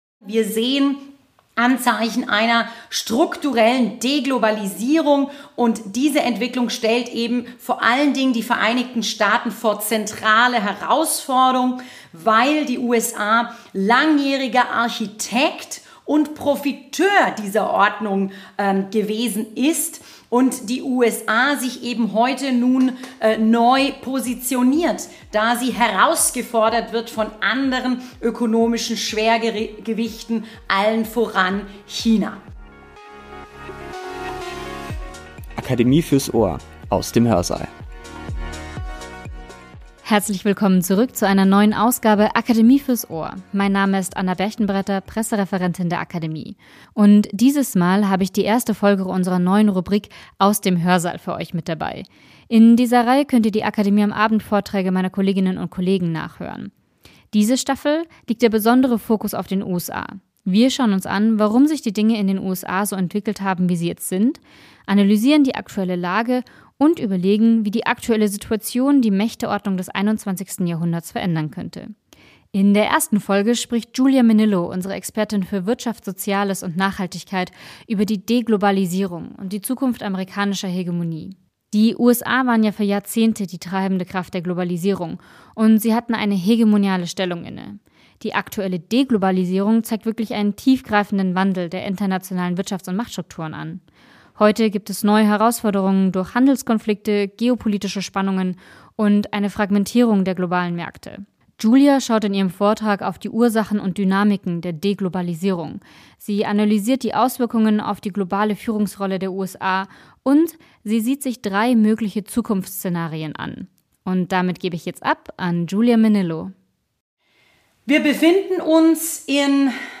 In unserer neuen Podcast-Rubrik "Aus dem Hörsaal" gibt es spannende Vorträge des Akademie-Kollegiums zum Nachhören.